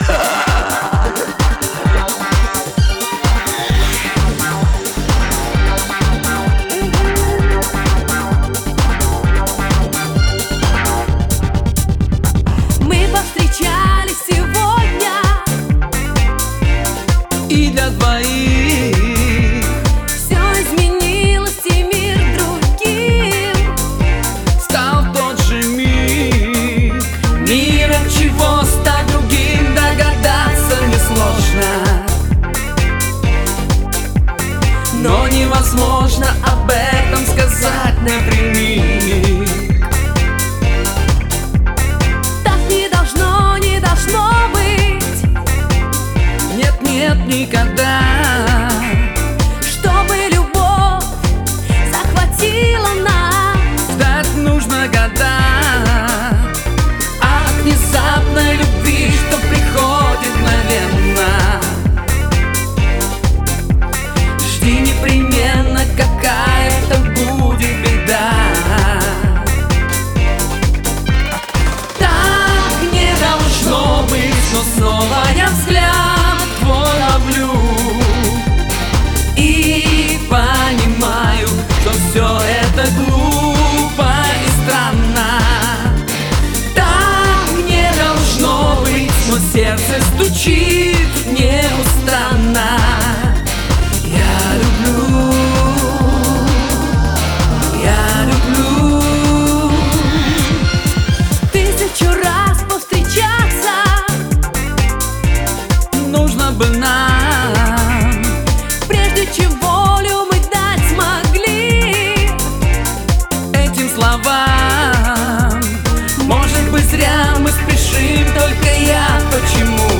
вокал, гитара
бэк вокал
альт-саксофон, клавишные
ударные, перкуссия